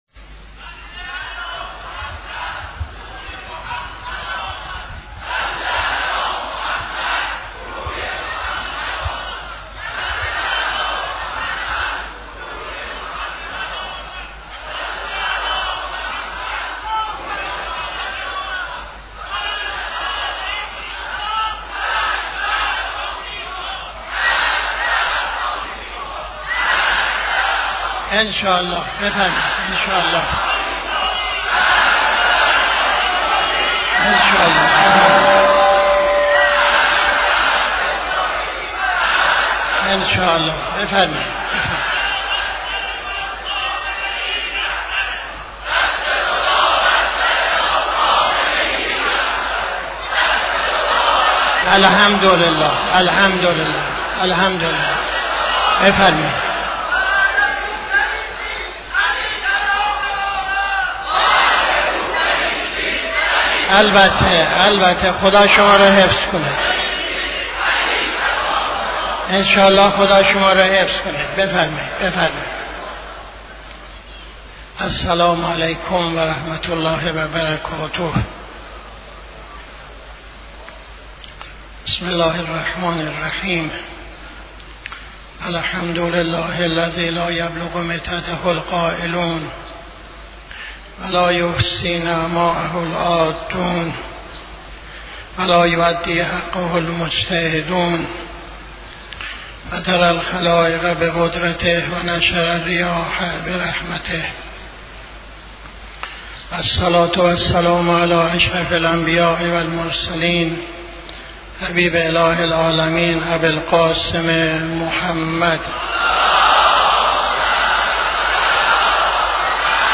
خطبه اول نماز جمعه 27-03-79